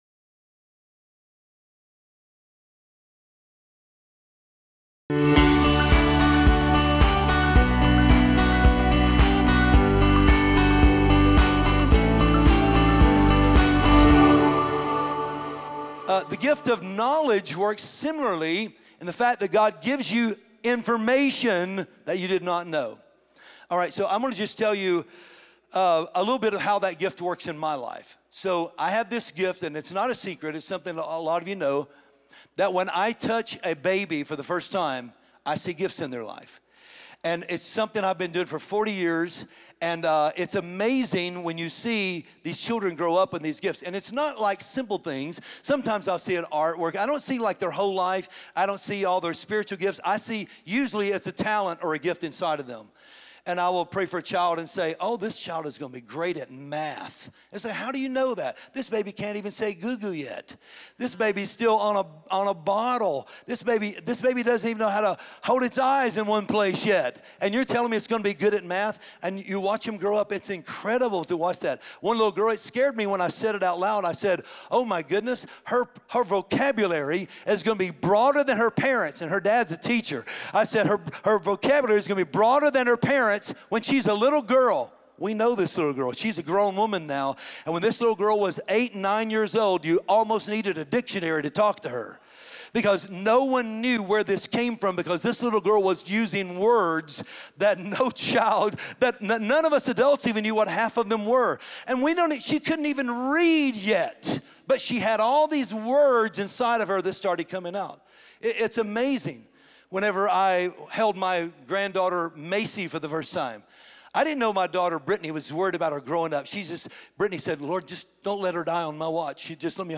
His passionate style of teaching will leave you hopeful and empowered to live your life based on the solid principles of the Word of God.
Sermons